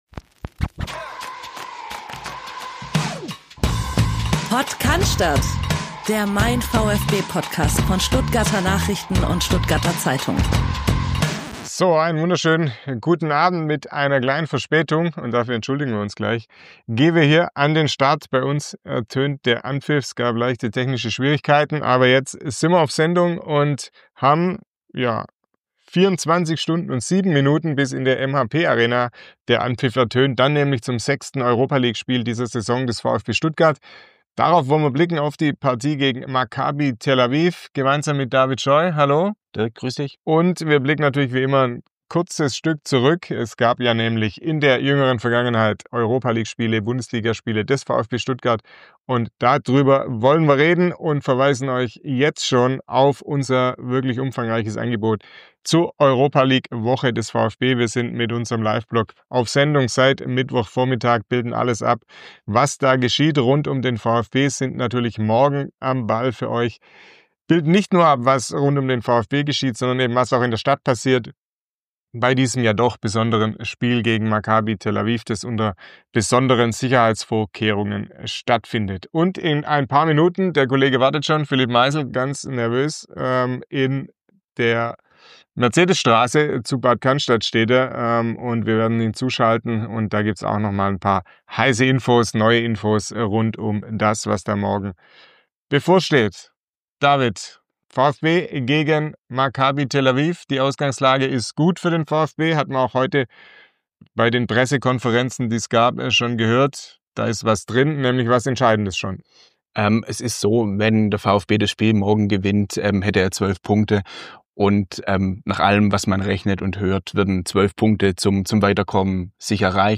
Es handelt sich um ein Audio-Re-Live des YouTube-Streams von MeinVfB.